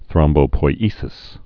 (thrŏmbō-poi-ēsĭs)